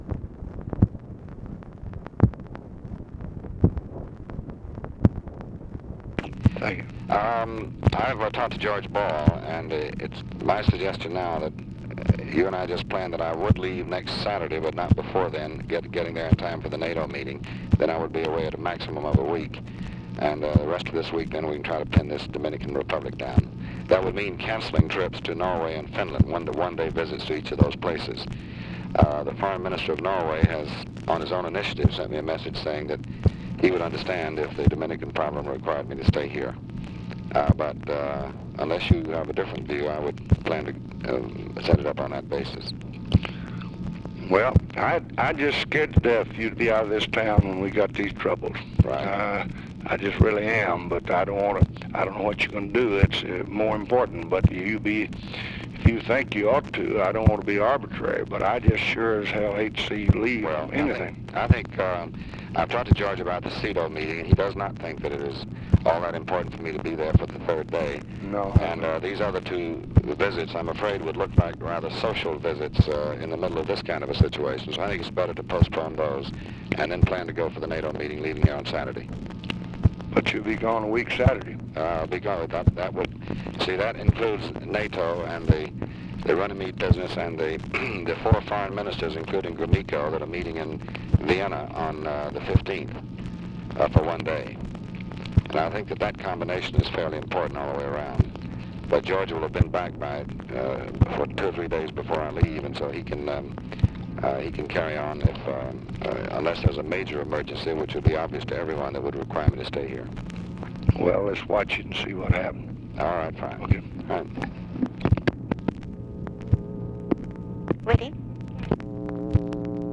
Conversation with DEAN RUSK, May 4, 1965
Secret White House Tapes